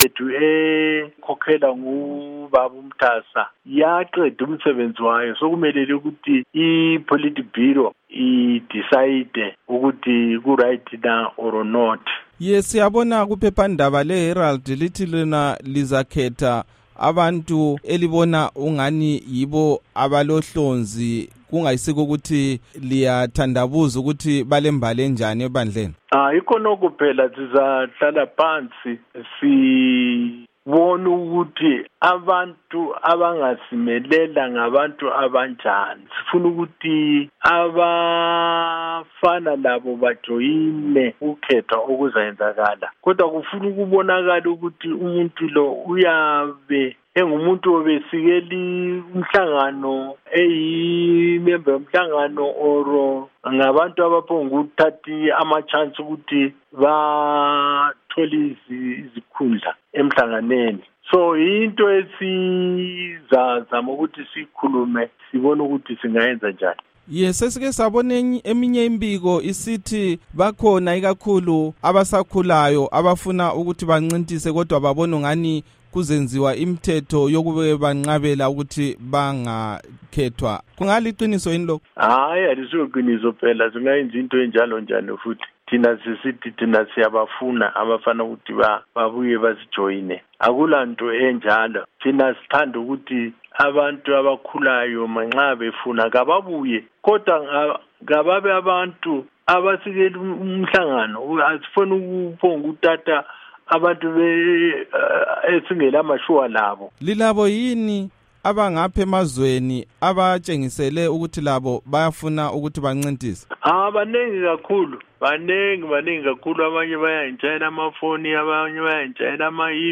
Ingxoxo loMnu. Rugare Gumbo